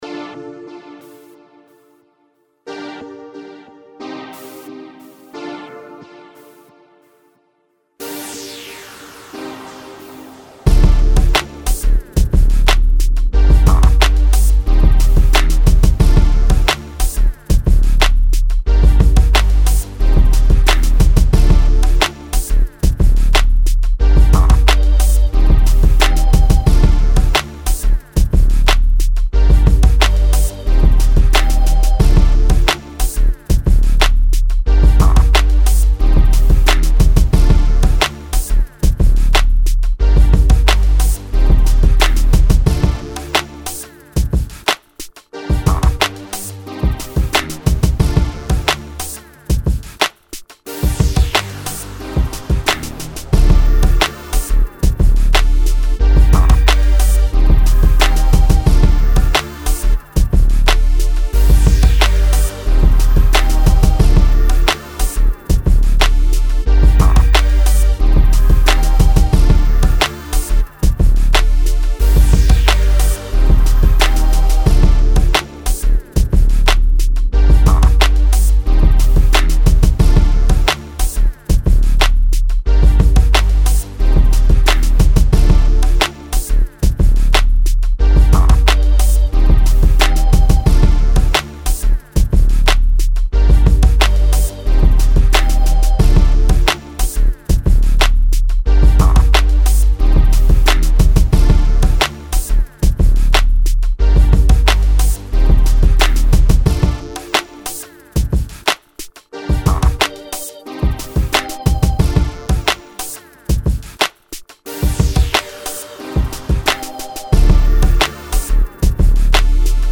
Club
R&B